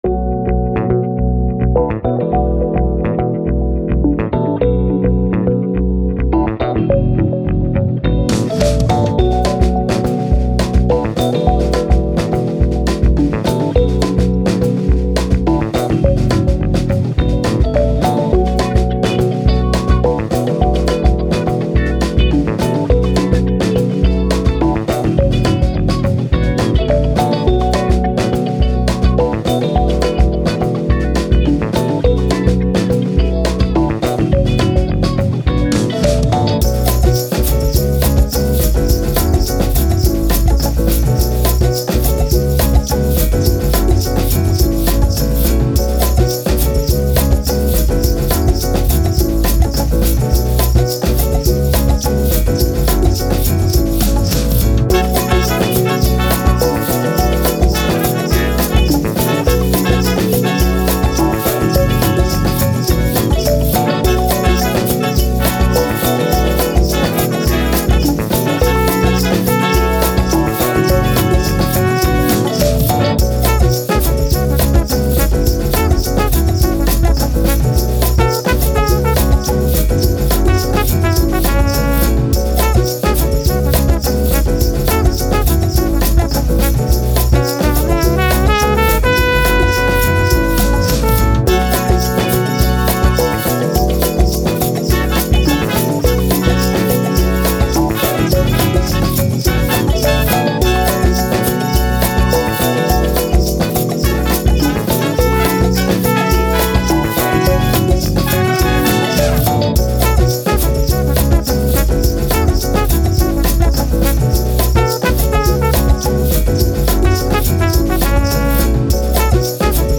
Breakbeat, Jazz, Funk, Upbeat, Action, Fun